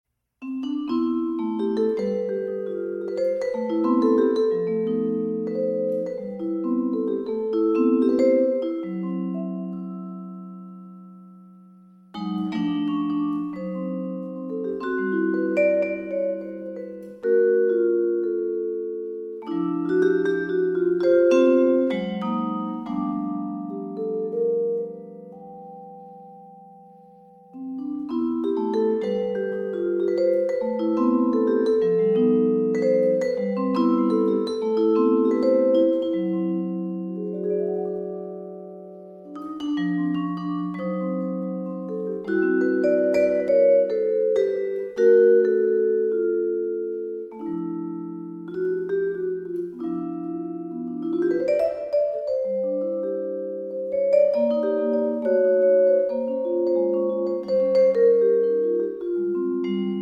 vibraphone